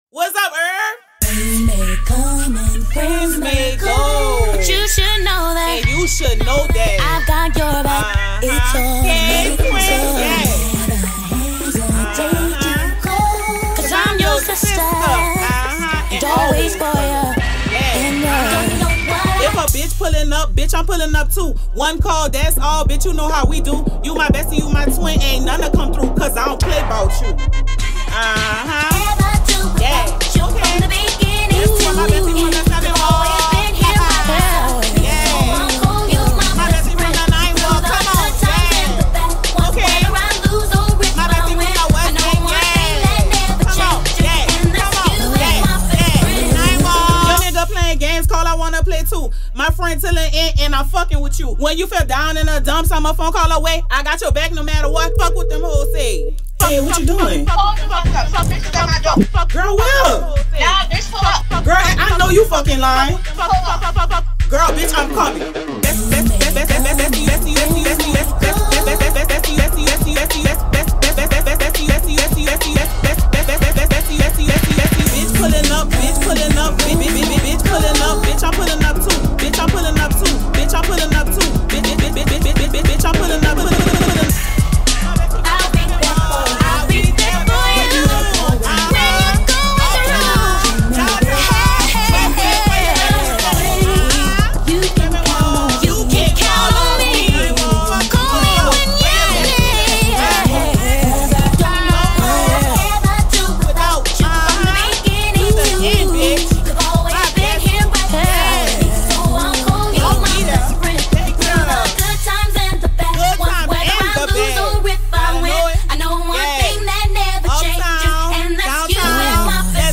Genre: Bounce.